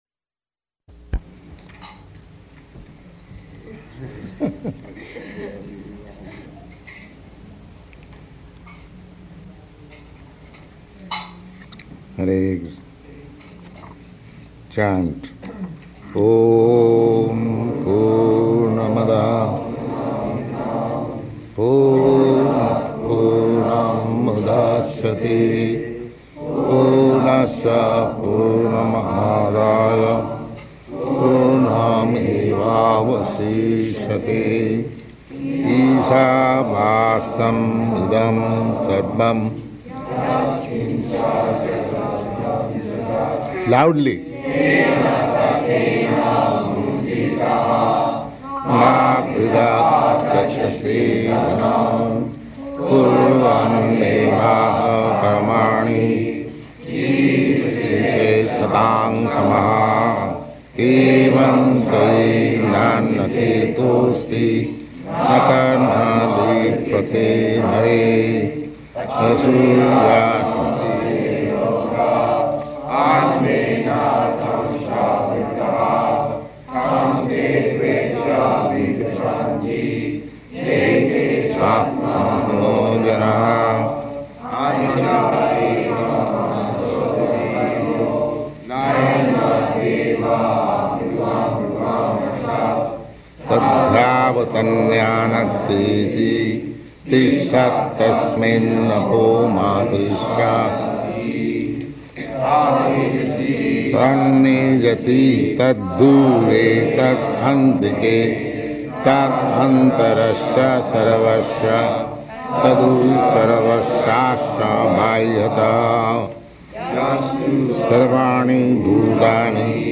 Śrī Īśopaniṣad, Mantra 9–10 --:-- --:-- Type: Sri Isopanisad Dated: May 14th 1970 Location: Los Angeles Audio file: 700514IP-LOS_ANGELES.mp3 Prabhupāda: [chuckles] Hare Kṛṣṇa.